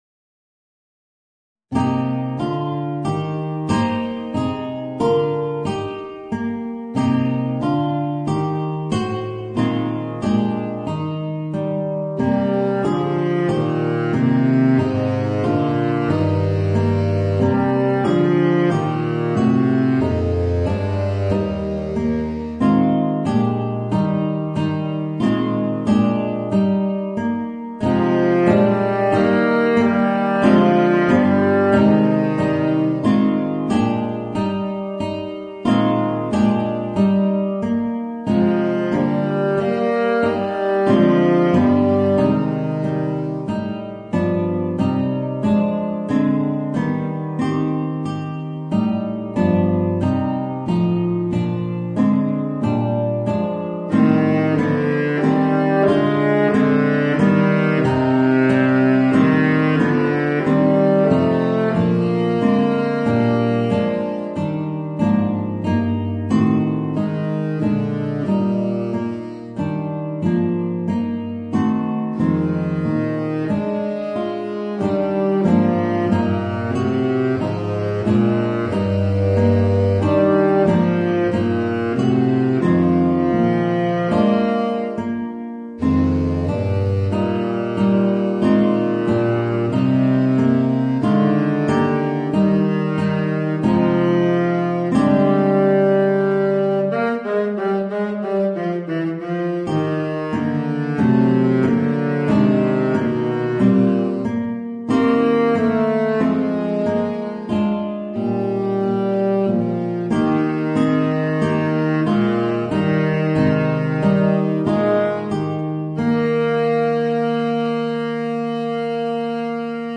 Voicing: Baritone Saxophone and Guitar